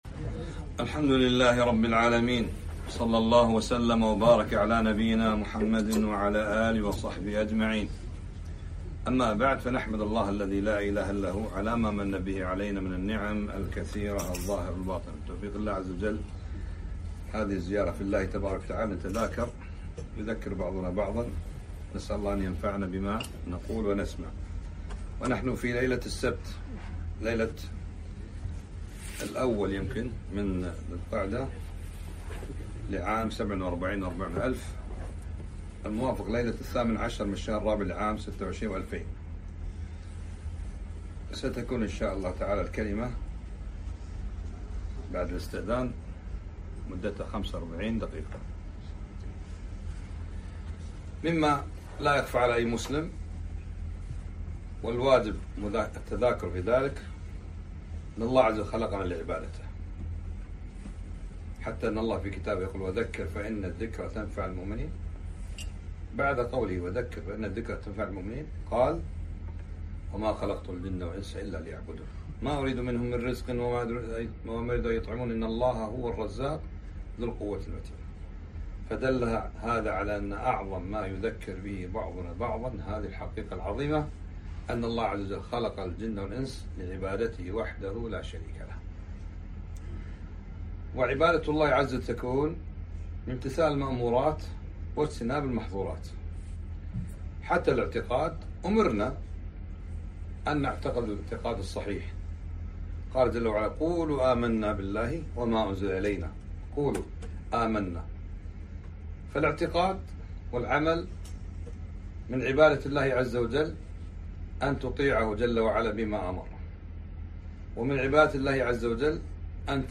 محاضرة - ( وذكر فإن الذكرى تنفع المؤمنين )